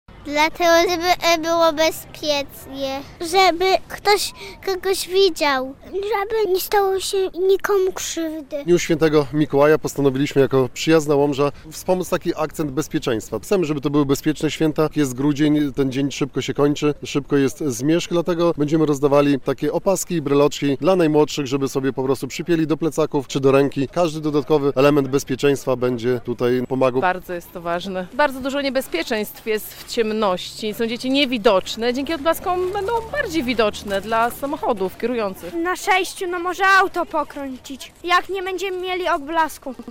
relacja
Kilku łomżyńskich radnych rozdawało w środę (6.12) elementy odblaskowe na Starym Rynku przed Halą Kultury.